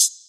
ClosedHH MadFlavor 7.wav